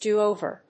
アクセントdò óver